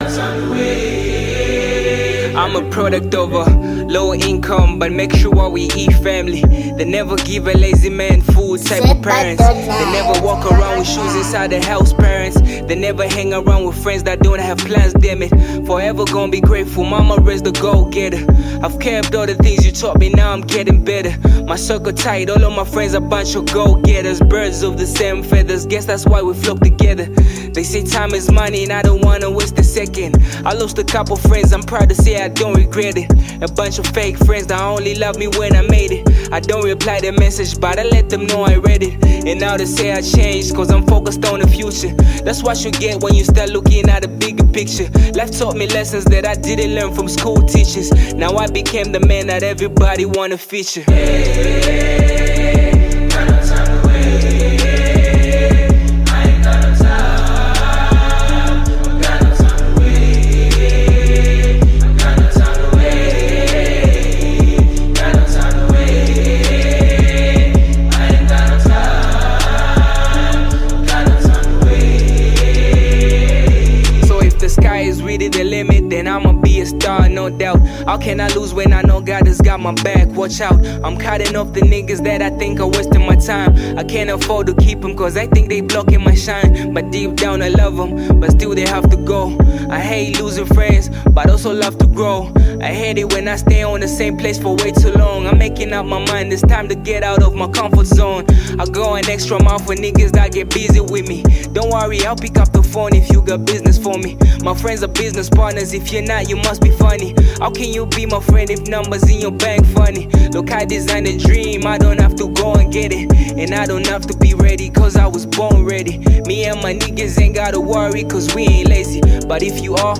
Category: Zambian Music